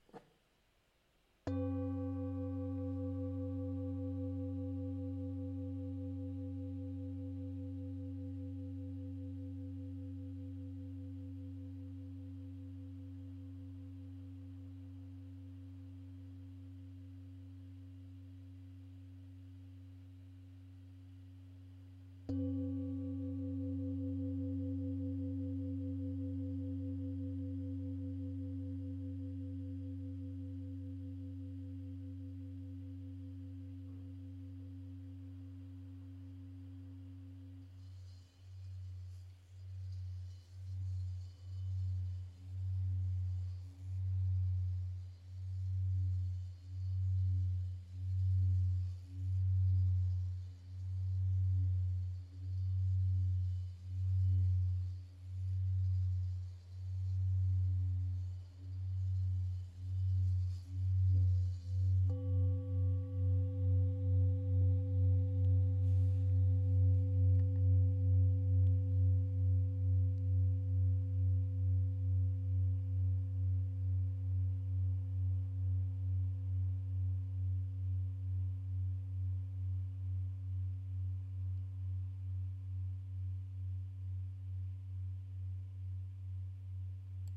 Campana Tibetana ULTA Nota FA(F) 2 90 HZ -P002
lega dei 7 metalli. Prodotto artigianale lavorato a mano attraverso battitura a mano.
Nota Armonica       DO(C) d4 270 HZ
Nota di fondo     FA(F) 2 90 HZ